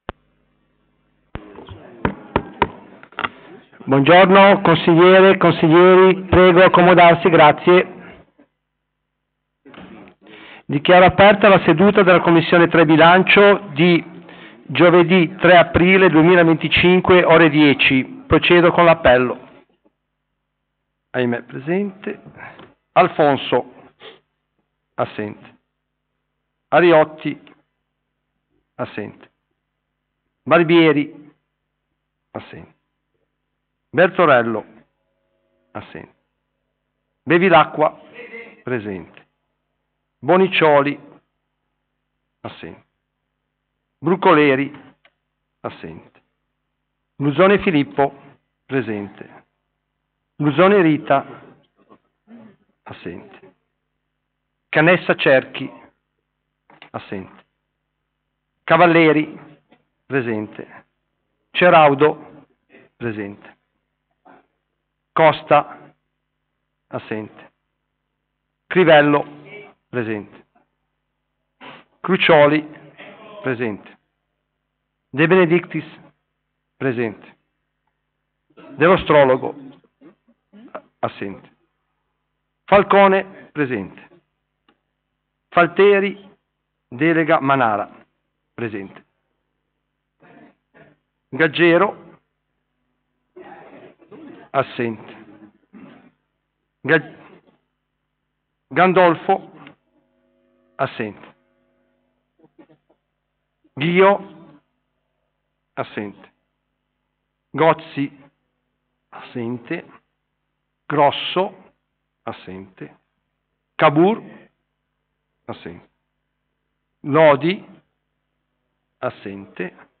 Commissione consiliare o Consiglio Comunale: 3 - Bilancio
Luogo: presso la sala consiliare di Palazzo Tursi - Albini